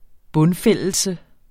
Udtale [ ˈbɔnˌfεlˀəlsə ]